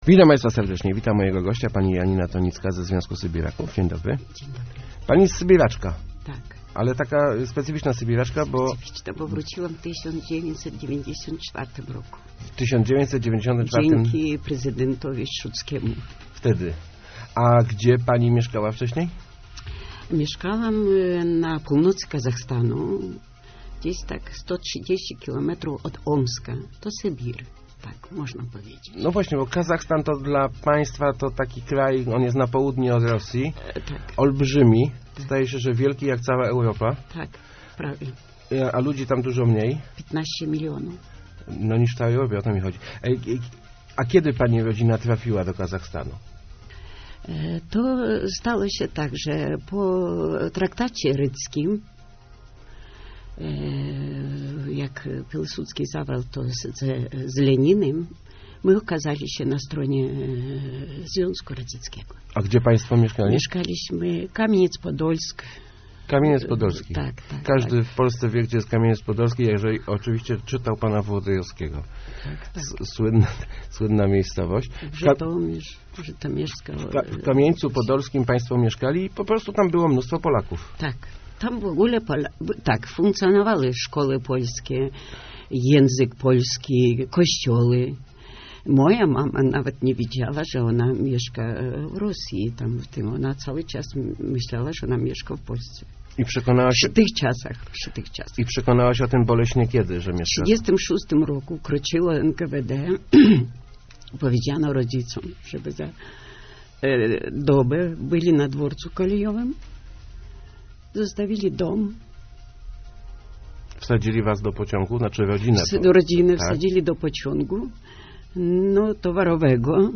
Związek Sybiraków w Lesznie chciałby postawić pomnik dedykowany polskim matkom, które cierpiały na zesłaniu. O tym, czym było zesłanie, opowiadała w Rozmowach Elki